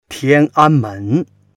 tian1an1men2.mp3